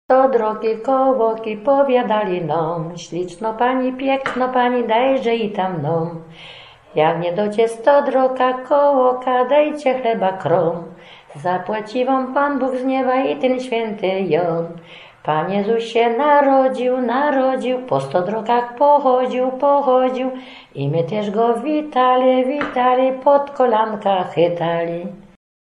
cenioną śpiewaczką ludową